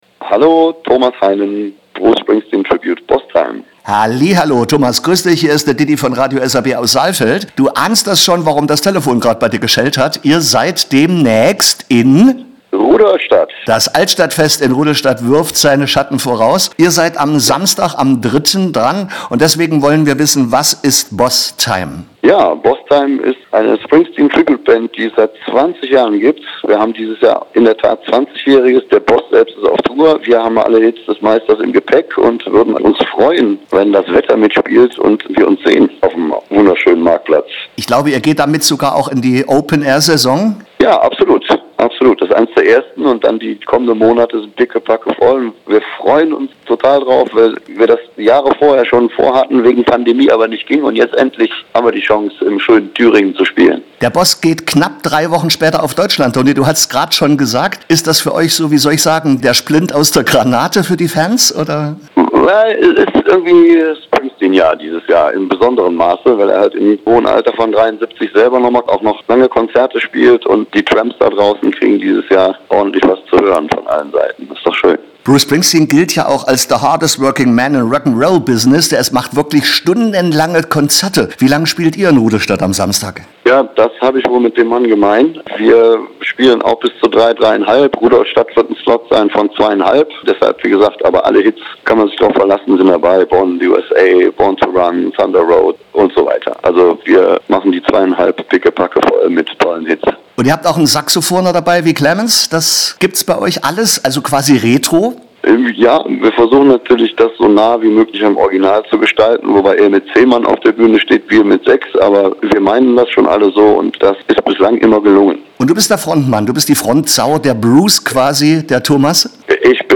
Radio SRB - Das Interview
im Interview mit